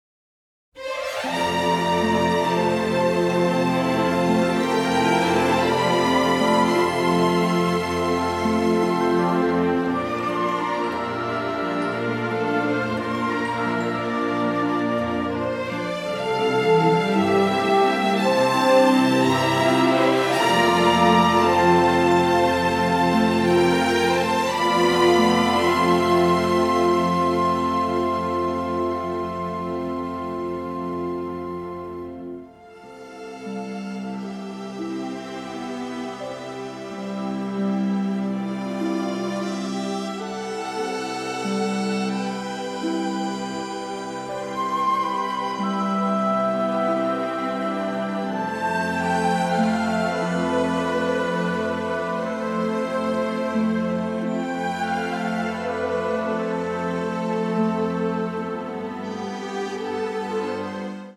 ORCHESTRA SUITE: